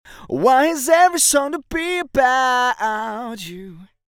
Vocal (Before)
Vocal-Before.mp3